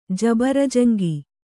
♪ jabarajaŋgi